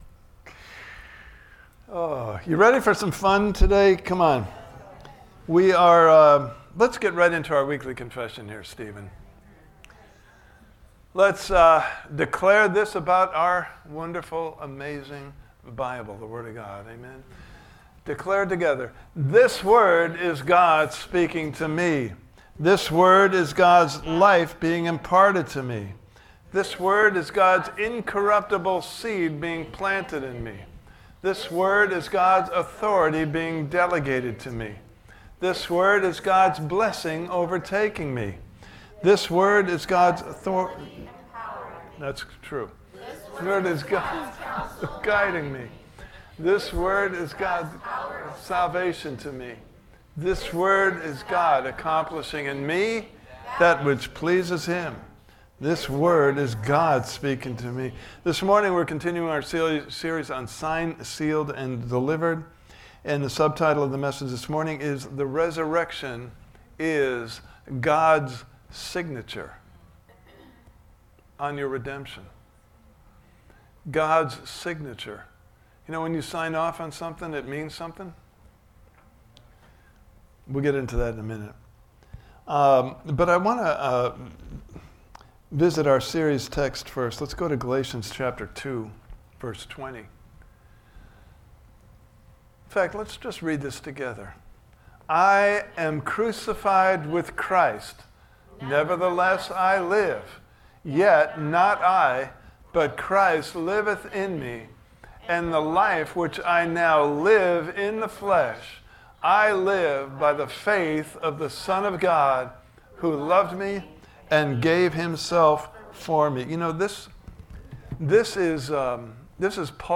Service Type: Sunday Morning Service « Part 1: As For Me …